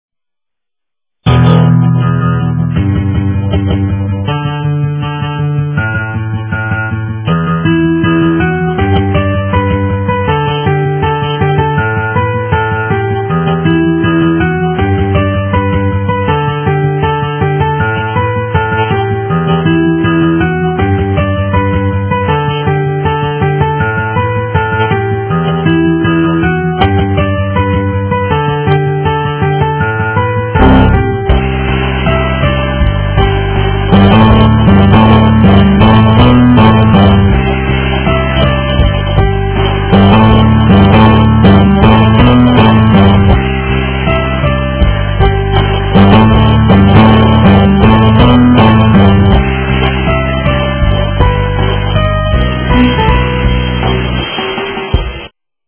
SOLO PART